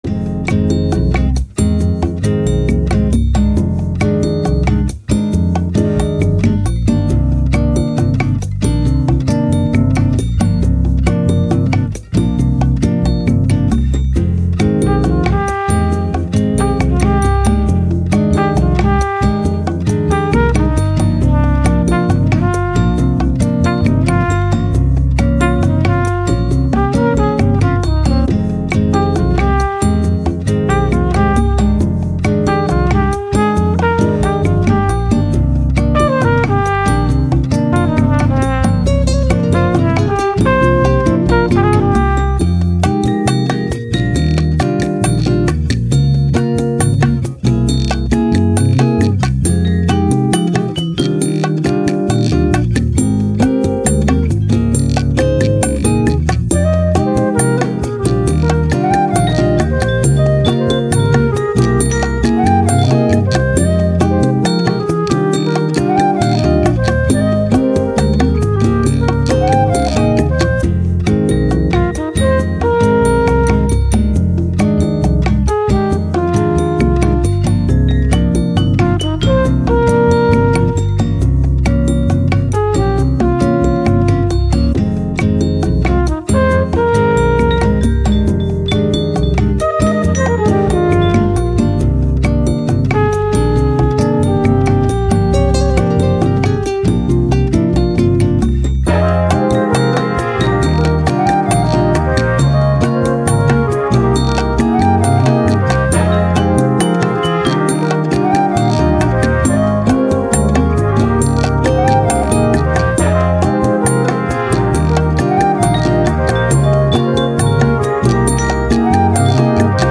Bossa Nova, Jazz, Elegant